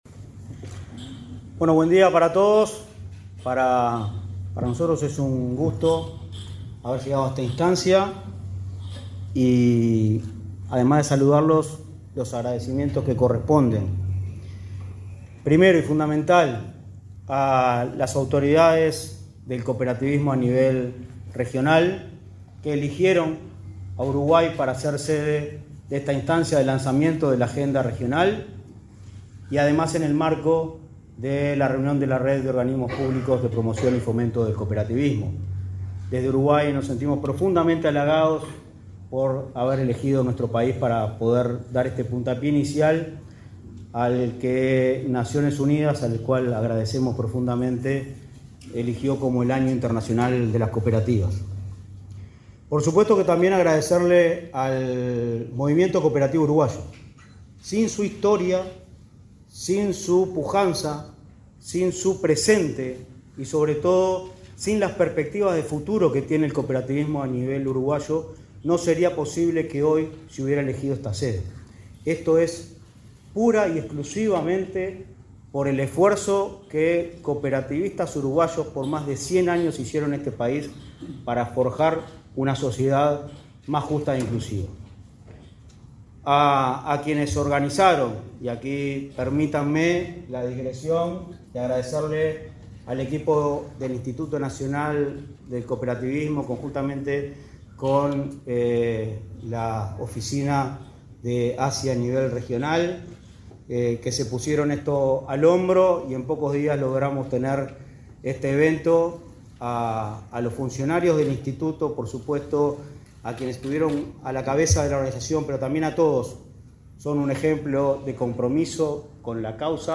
Palabras de autoridades en acto de Inacoop
Palabras de autoridades en acto de Inacoop 20/02/2025 Compartir Facebook X Copiar enlace WhatsApp LinkedIn El presidente del Instituto Nacional del Cooperativismo (Inacoop), Martín Fernández, y el subsecretario de Trabajo y Seguridad Social, Daniel Pérez, participaron en el lanzamiento de la agenda regional del Año Internacional de las Cooperativas, que se realizó este jueves 20 en Punta del Este, departamento de Maldonado.